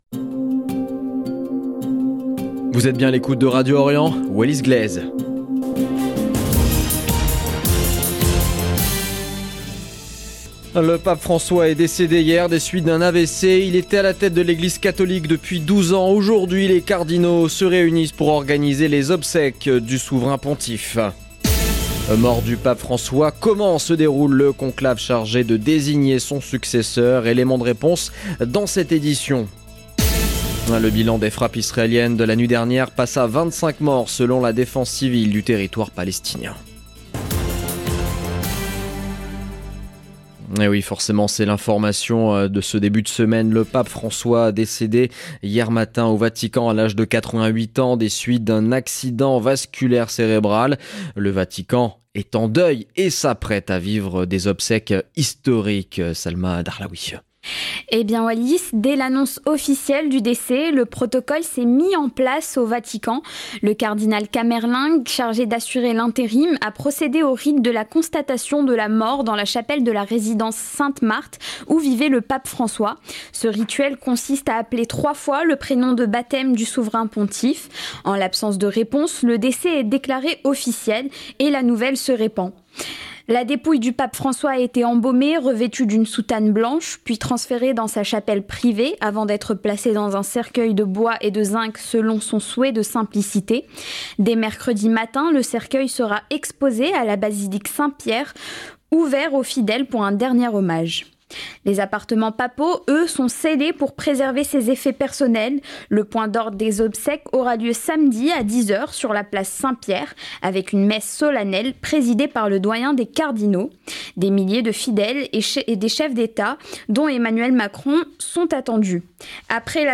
LE JOURNAL EN LANGUE FRANÇAISE DE MIDI DU 22/04/2025